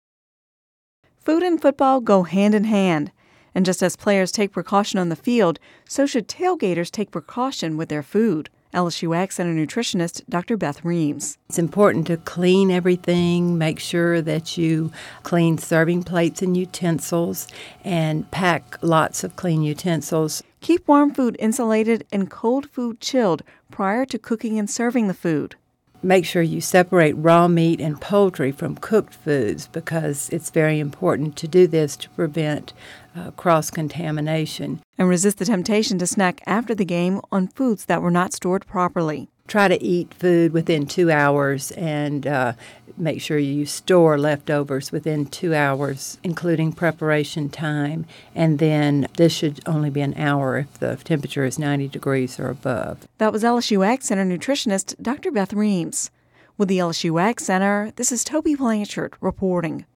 (Radio News 10/04/10) Food and football go hand in hand. Just as players take precautions on the field, tailgaters should take precautions with their food.